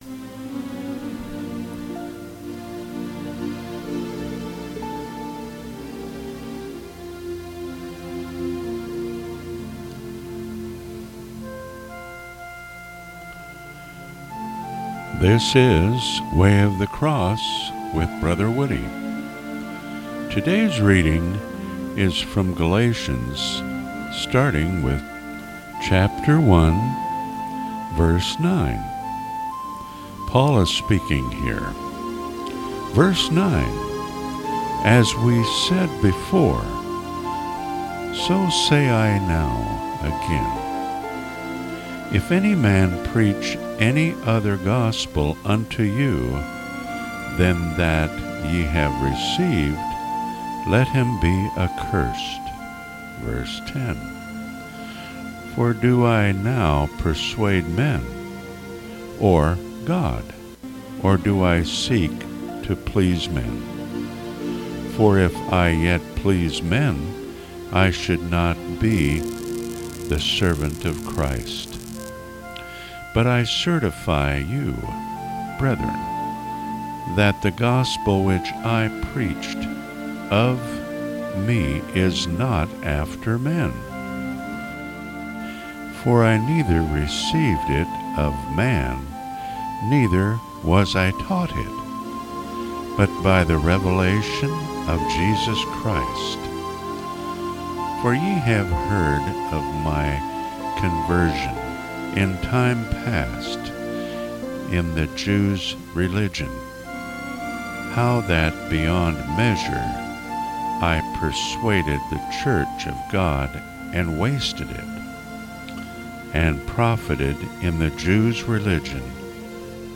Bible readings